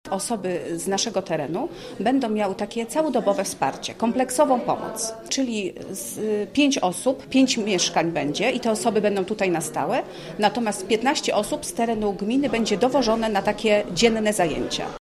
Mówi przewodnicząca Rady Gminy w Bogdańcu, Beata Wiśniewska: